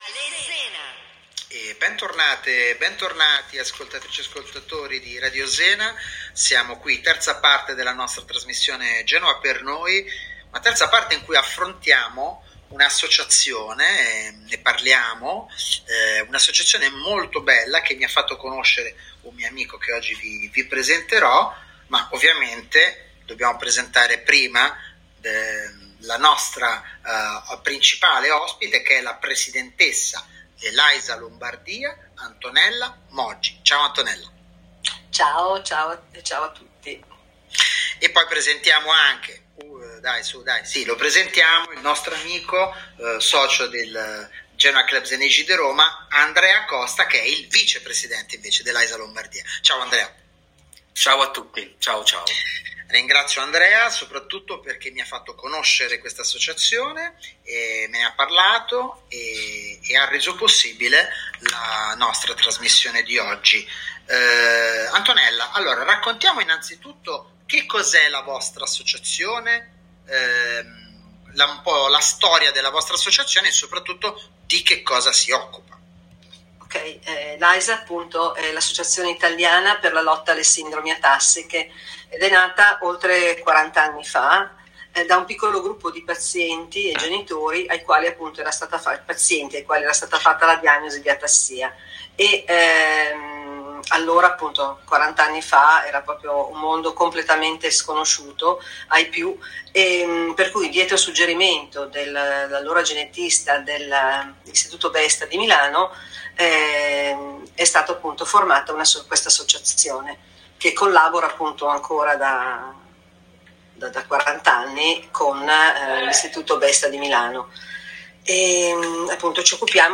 Intervista a AISA Lombardia su Radio Zena nella trasmissione Genoa per Noi
Genoa-per-noi-AISA-Lombardia-ODV.m4a